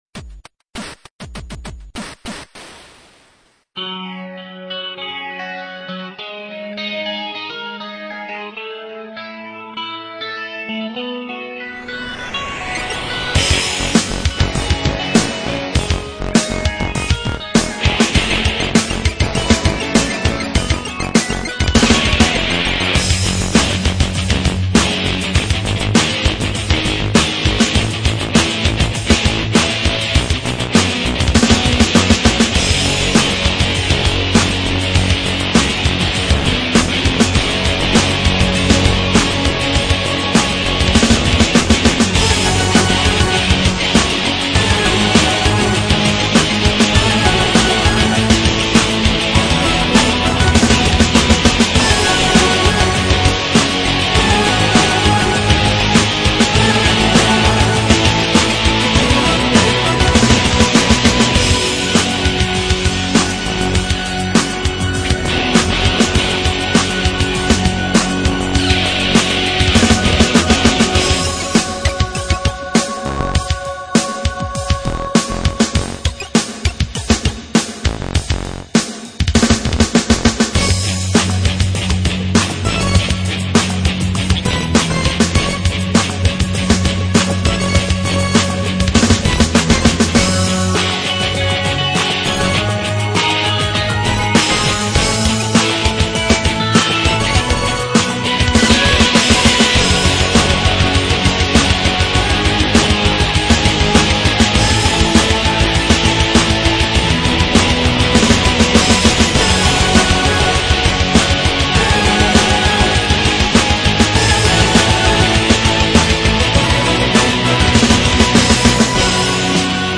Musica arcade